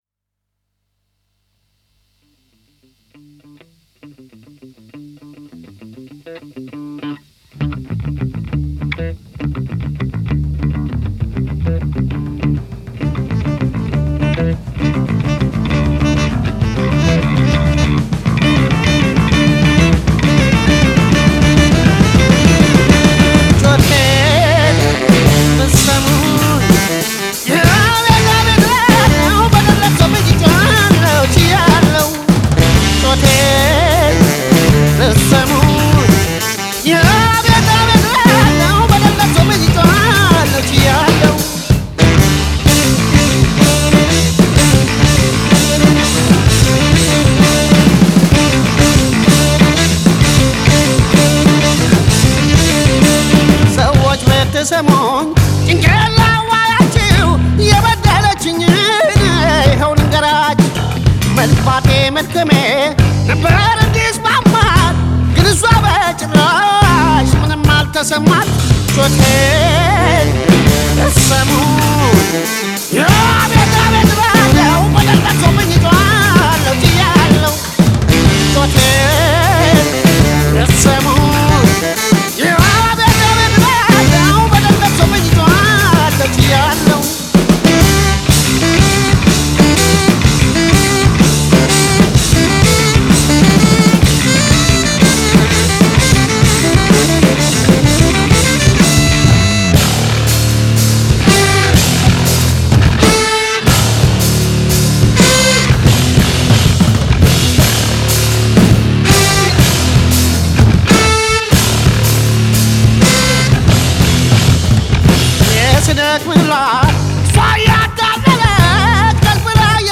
Un sens du groove unique !
noise rock